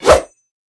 katana_slash1.wav